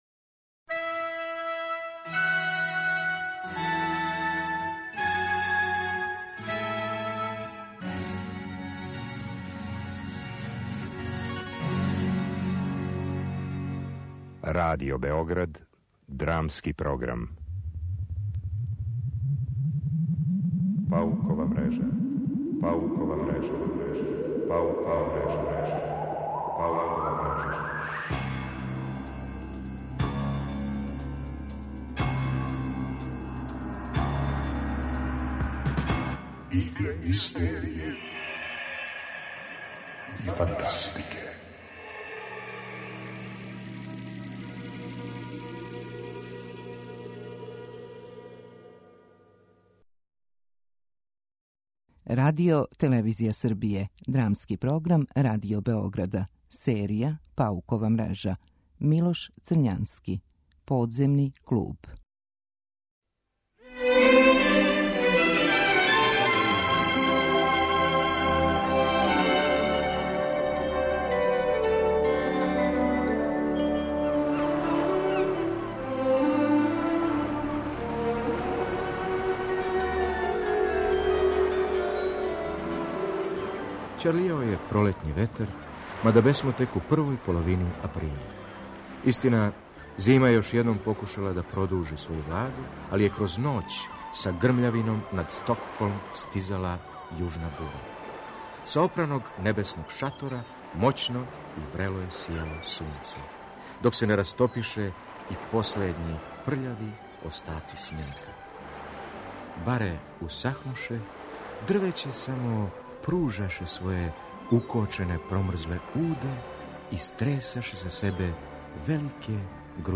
Драмски програм: Паукова мрежа
drama.mp3